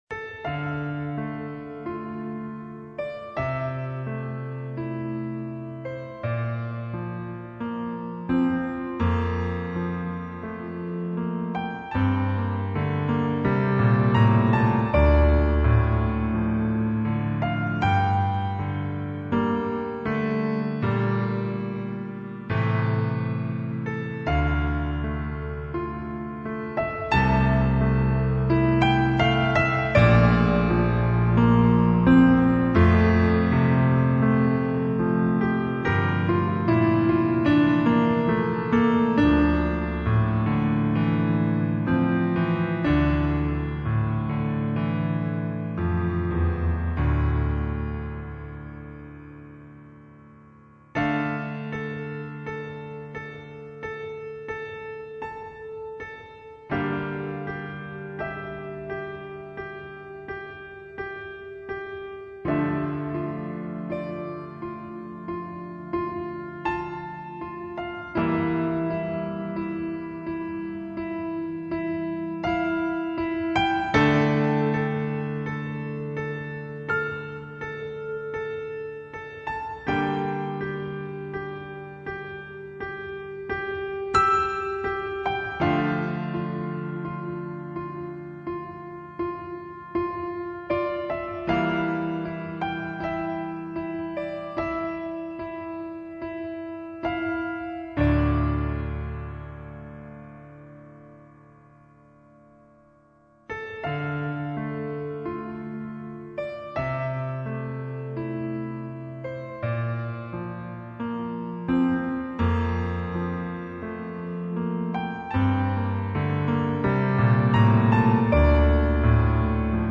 唱片透出了一种不安，令人窒息的莫名的悲哀